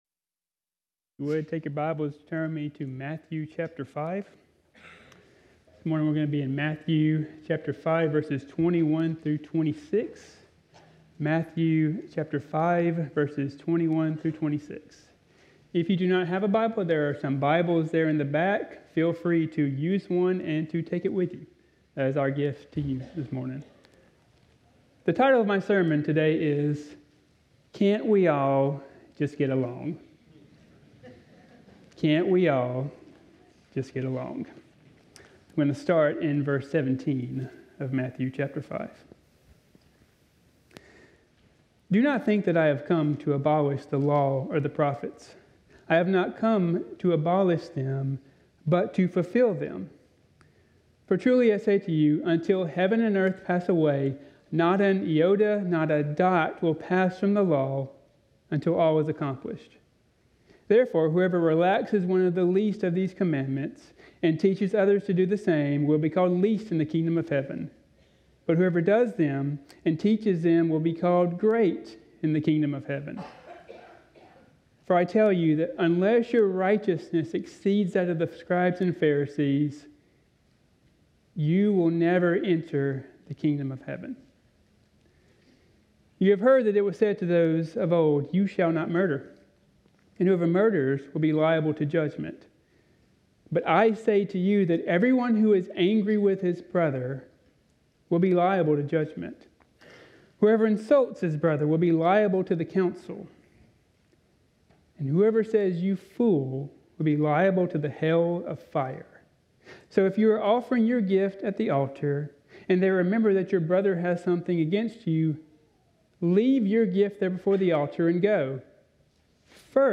Parkway Sermons Can't We All Just Get Along?